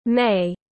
Tháng 5 tiếng anh gọi là may, phiên âm tiếng anh đọc là /meɪ/
May /meɪ/